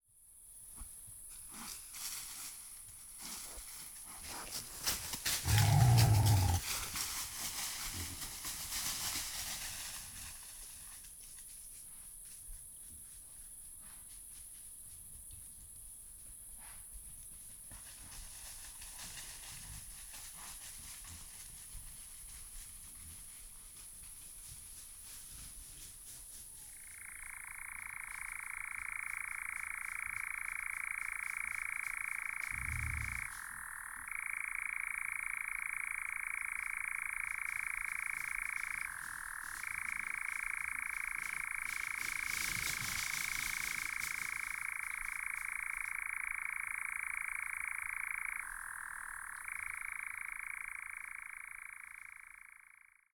Los incluidos en este primer álbum de la colección —ambientes nocturnos y crepusculares captados en diversas regiones de France métropolitaine— fueron realizados a lo largo de una década, entre 2012 y 2022, durante las distintas estaciones del año.
Fin de nuit dans les Cévennes, sangliers, engoulevent d’Europe (4:44); 11.